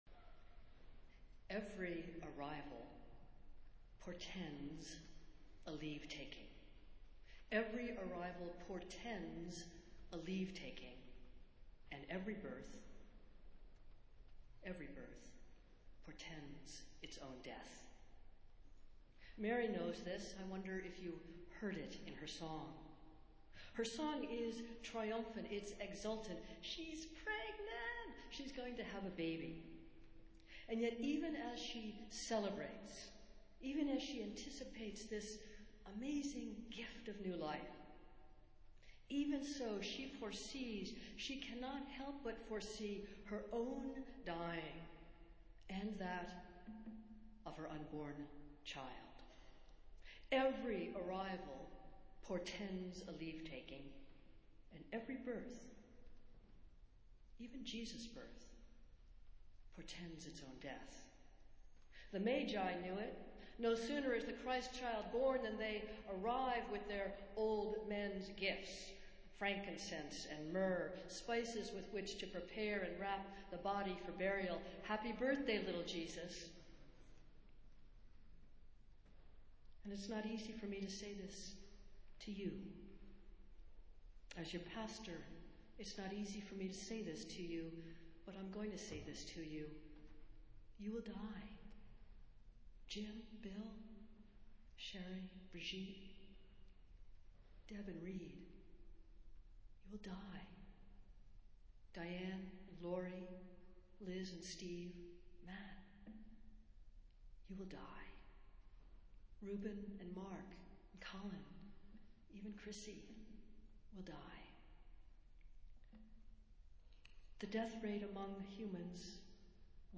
Festival Worship - Third Sunday in Advent